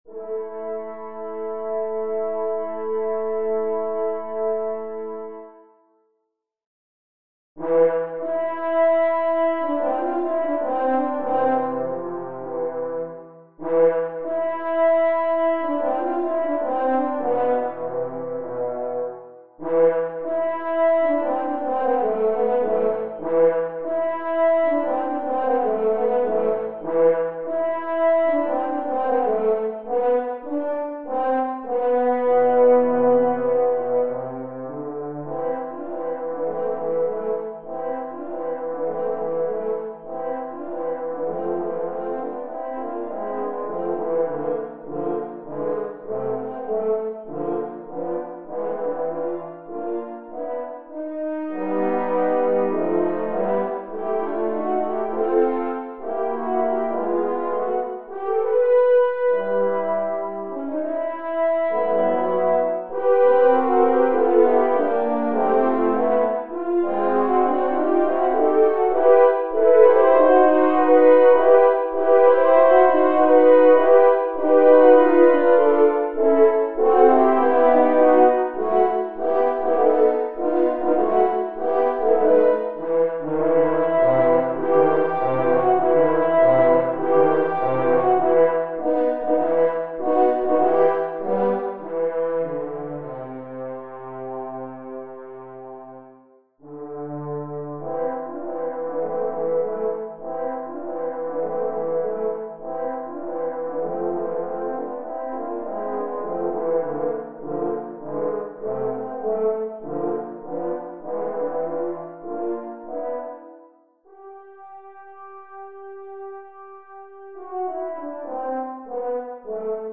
Viking Horn Trio No. 6 (Portrait Of A Viking Queen) (MIDI)